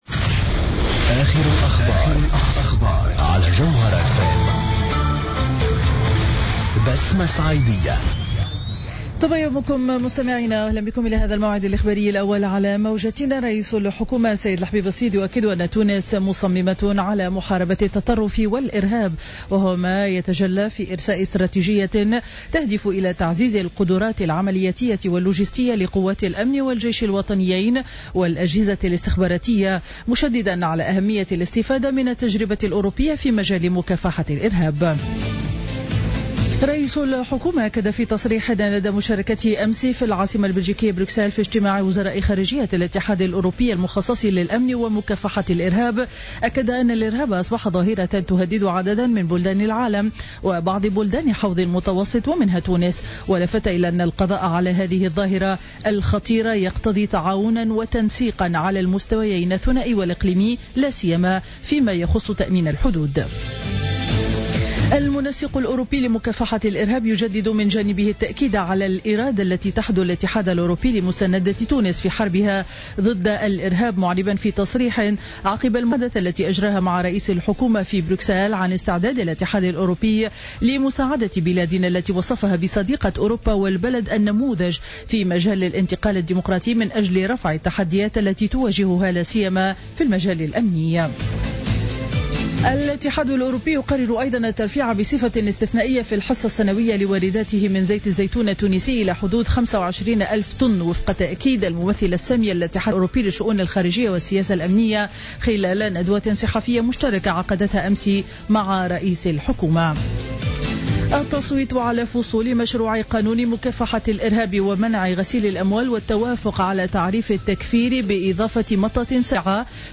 نشرة أخبار السابعة صباحا ليوم الثلاثاء 21 جويلية 2015